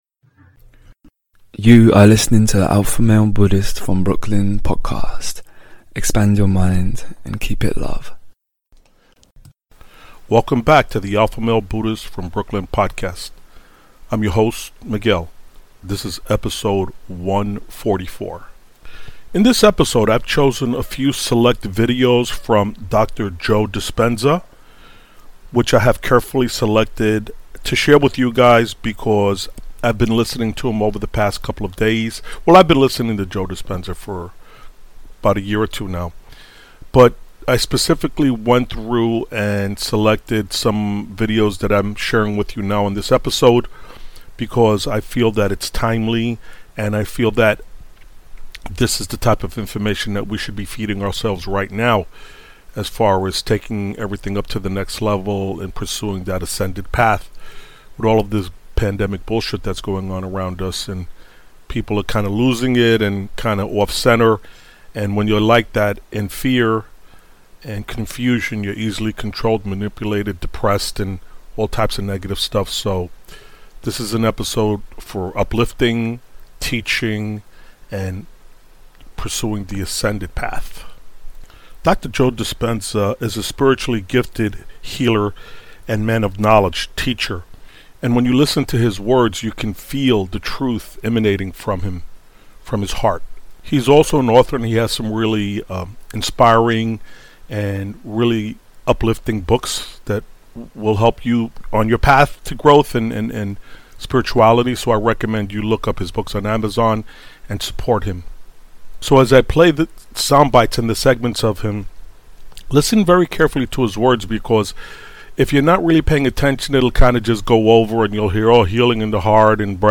EP 144 - Must Listen - Awaken to who you really are and Live the Ascended true Life of Joy and Fulfillment - Speaker Dr. Joy Dispenza Dropping Real truth and Knowledge